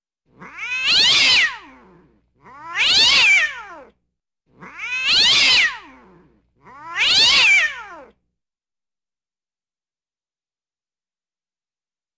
Arg Katt (Katter)
Djur , Katter